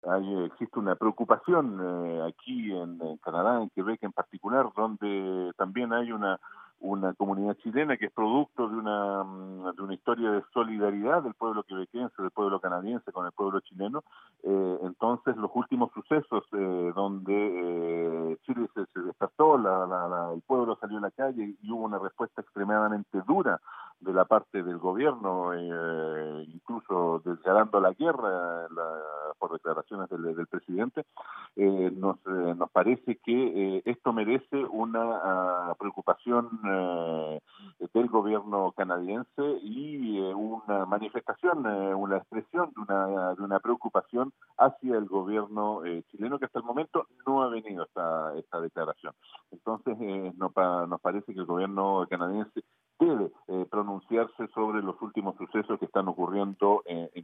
Conversamos con el diputado Andrés Fontecilla quien nos dijo que la situación de los derechos humanos en Chile es extremadamente preocupante y que hay un interés en Canadá y en Quebec sobre lo que sucede en ese país del sur del continente (0:57):